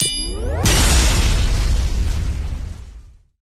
golden_crate_explosion_01.ogg